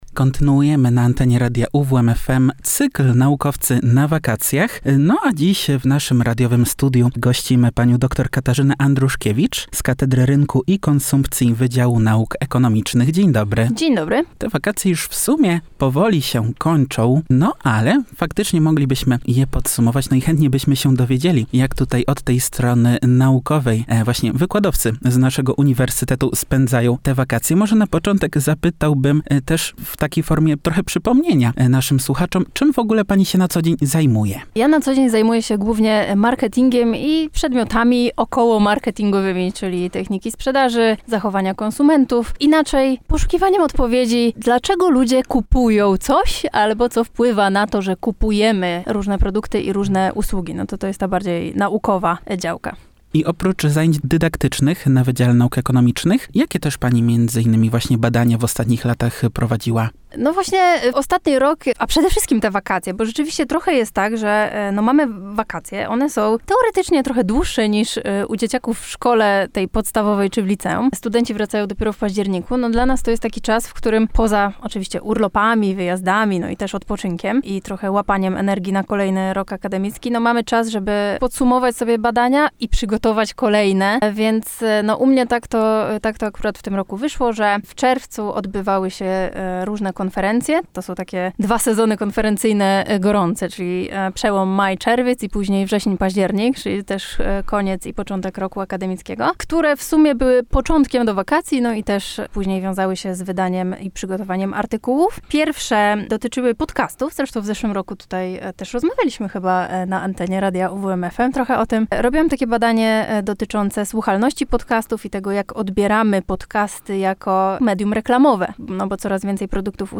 Jednak zanim nadejdzie październik, w studiu Radia UWM FM mówiła o tym, jak upłynął […]
Czy w przypadku naukowca da się w pełni oddzielić pracę od czasu wolnego? Zapraszamy do wysłuchania całej rozmowy!